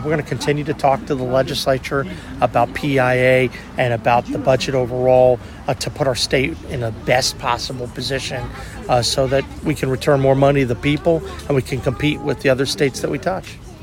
At Friday’s ribbon cutting for the new judicial annex to Mineral County’s courthouse, WCBC had the opportunity to ask West Virginia Governor Patrick Morrisey about plans to call the legislature back to Charleston in a special session. Morrisey said earlier in the year he had planned such a session for September to address employee insurance issues.